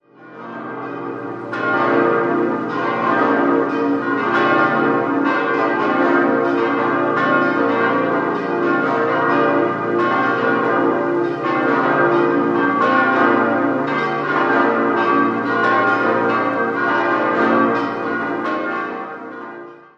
Auch der Turm war ursprünglich 8 Meter höher. 6-stimmiges Geläut: b°-c'-d'-f'-g'-b' Die kleinste Glocke wurde 1869 von Bachmair gegossen, alle anderen sind Gussstahlglocken des Bochumer Vereins in V12-Rippe aus dem Jahr 1948.